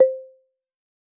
tabswitch.wav